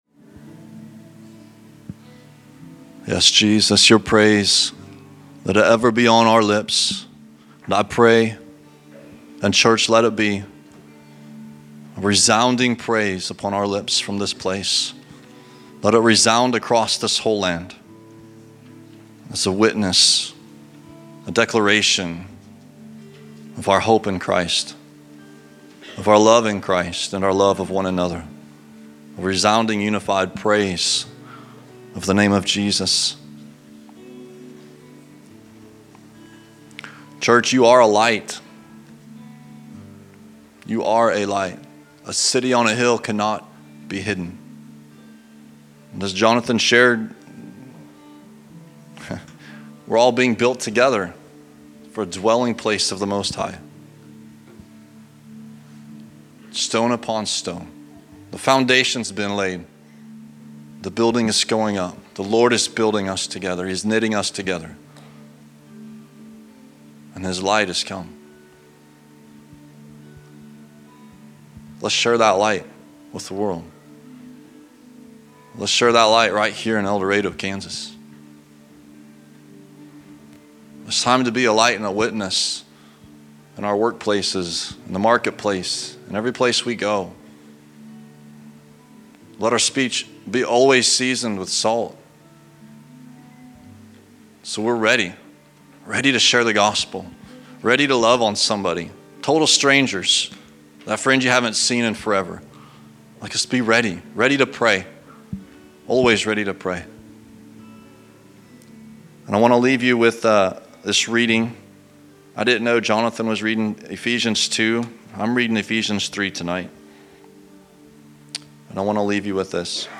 Location: El Dorado